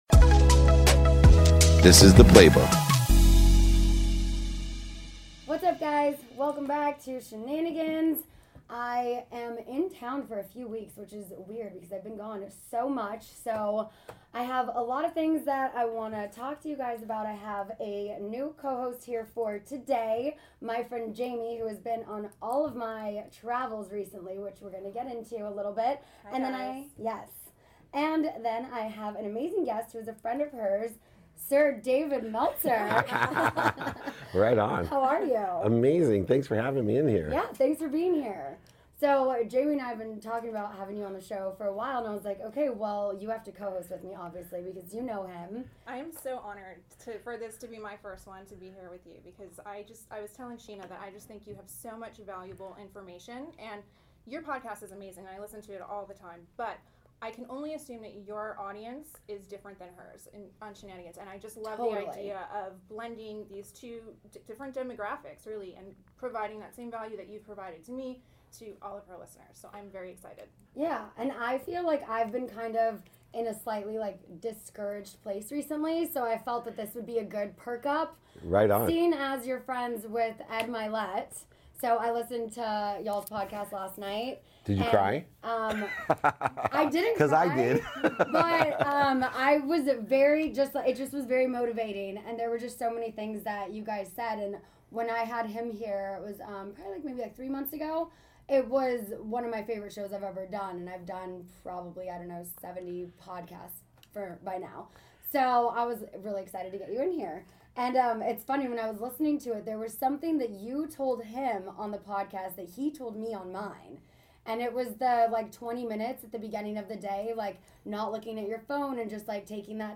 Today's episode is from my interview on the Scheananigans with Scheana Shay Podcast, sharing my personal story of losing everything and rebuilding my life. We discussed the importance of taking time for oneself, self-reflection, and giving back to others, the value of simplifying one's life, and focusing on what truly matters and, highlighted the importance of putting faith in oneself and being open and communicative with those we care about.